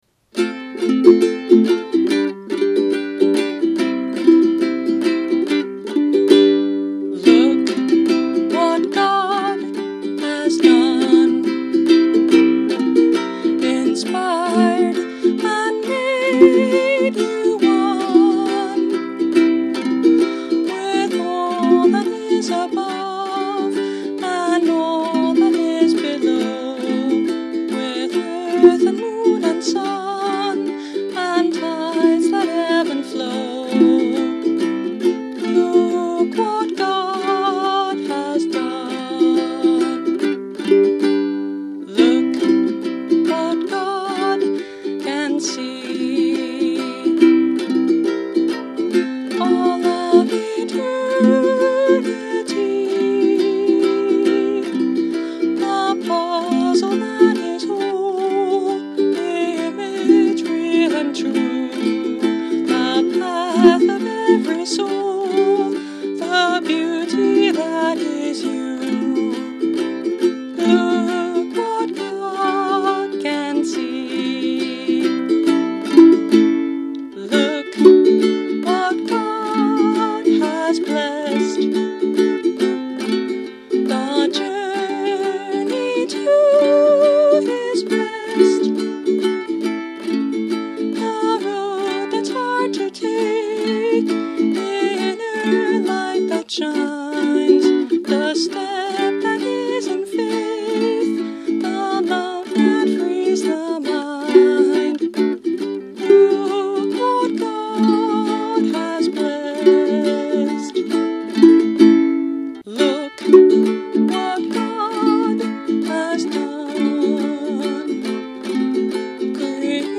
Instrument: Eventide – Mahogany Concert Ukulele